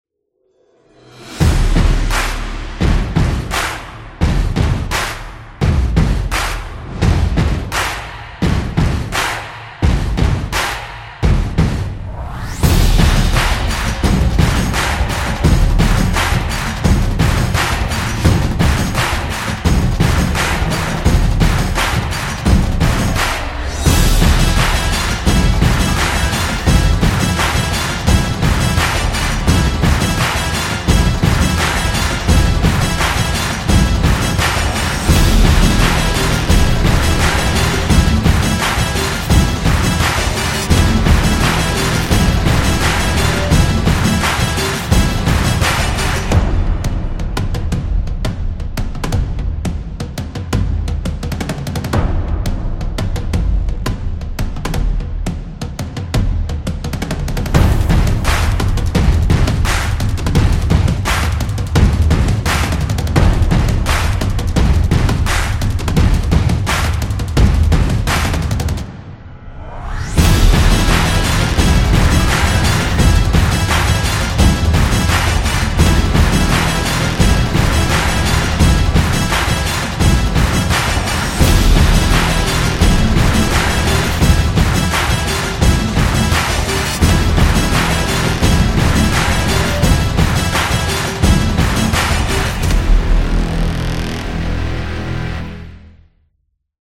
Percussion